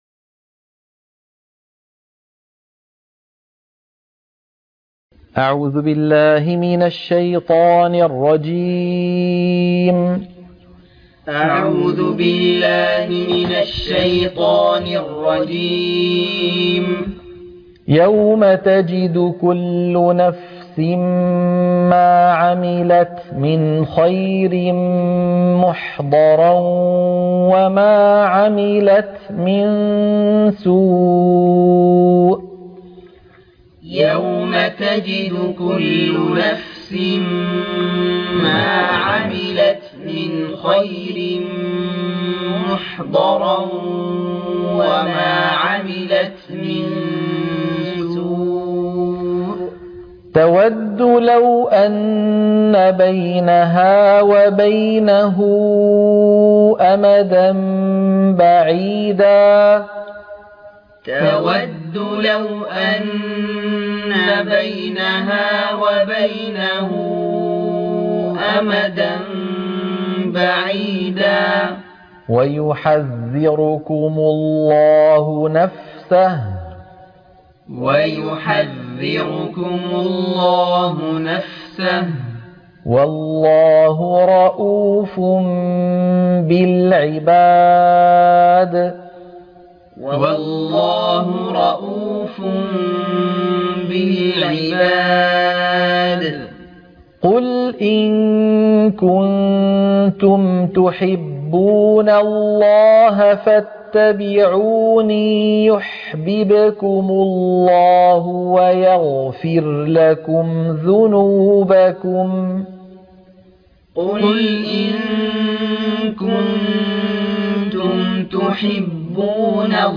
تلقين سورة ءال عمران - الصفحة 54 - التلاوة المنهجية - الشيخ أيمن سويد